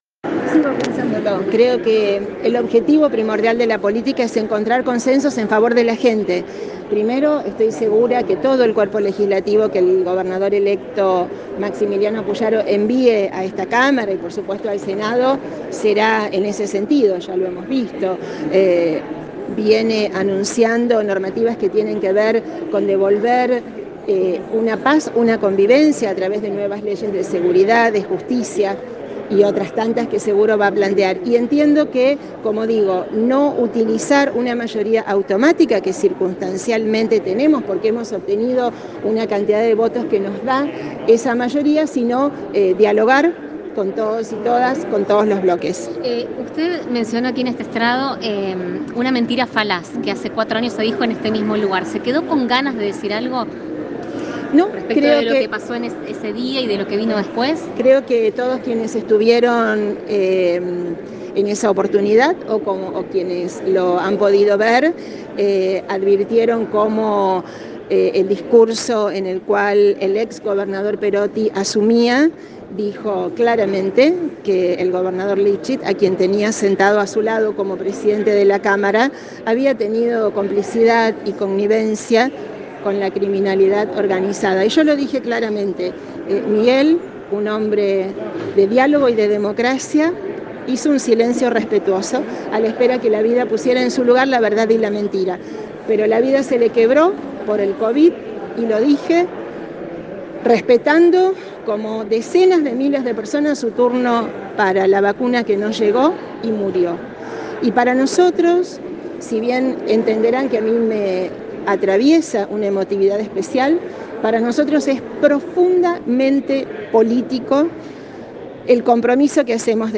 Escucha la palabra de Clara García en Radio EME: